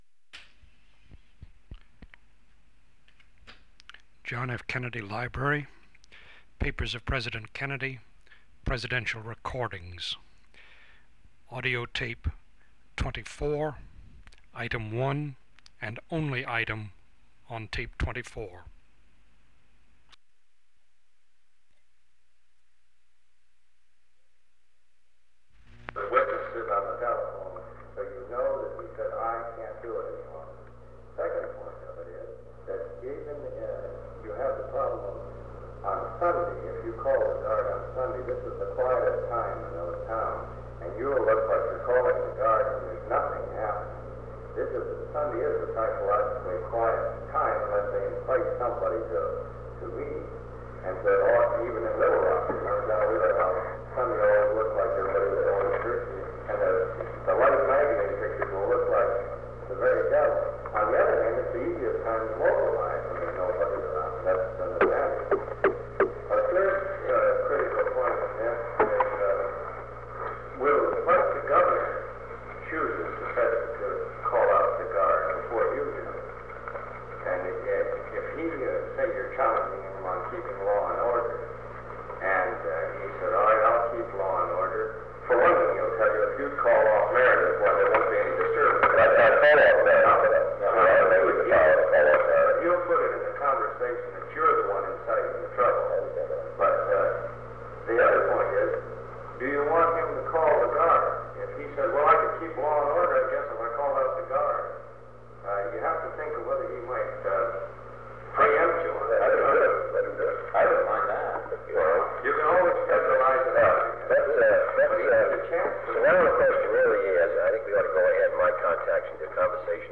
Secret White House Tapes | John F. Kennedy Presidency Meeting on the Crisis at the University of Mississippi Rewind 10 seconds Play/Pause Fast-forward 10 seconds 0:00 Download audio Previous Meetings: Tape 121/A57.